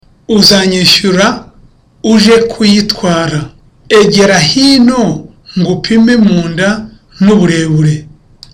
(Smiling)